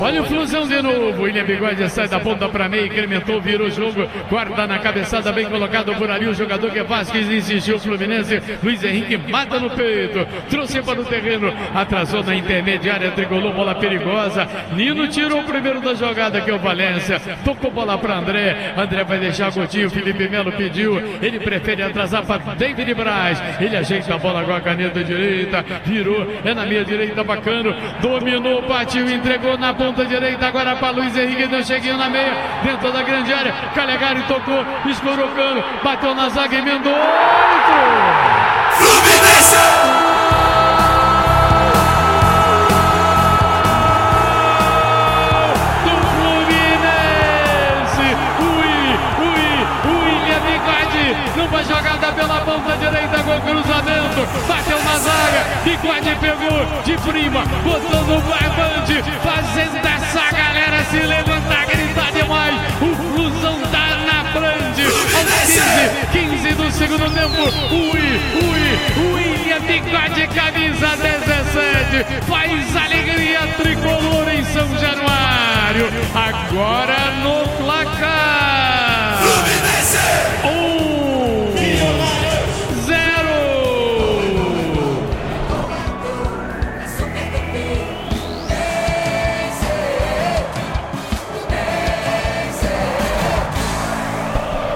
Ouça os gols da vitória do Fluminense sobre o Millonarios pela Libertadores com a narração do Garotinho